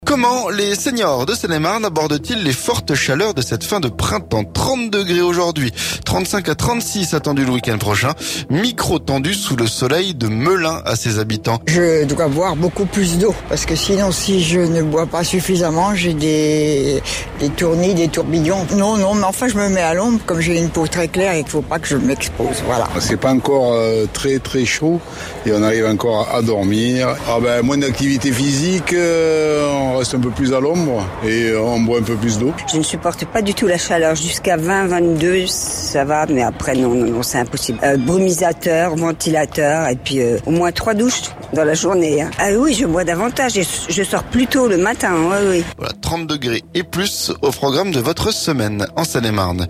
Comment les séniors de Seine-et-Marne abordent-ils les fortes chaleurs de cette fin de printemps ? 30 degrés aujourd'hui, 35 à 36 attendus le week-end prochain... Micro tendu sous le soleil de Melun à ces habitants.